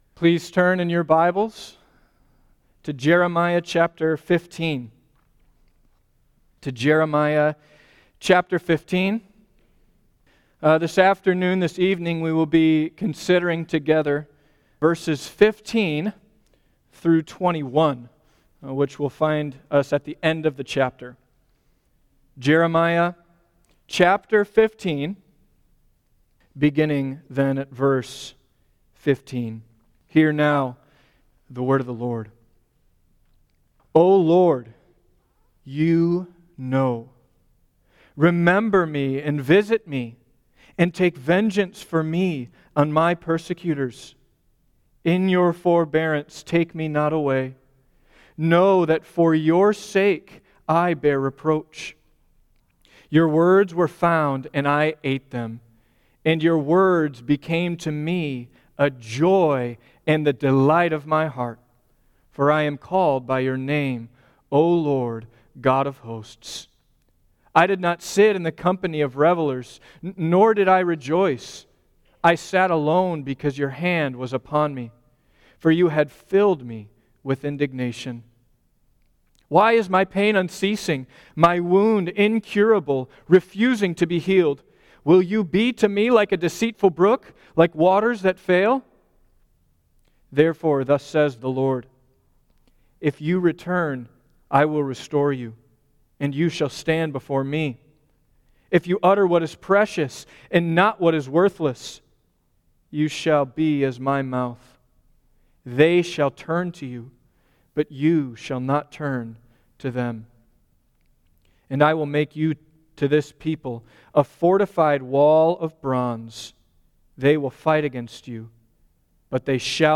Suffering Series Guest Preacher Book Jeremiah Watch Listen Save In Jeremiah 15:15–21, the prophet cries to the Lord in his suffering, and he clings to God’s promise of restoration.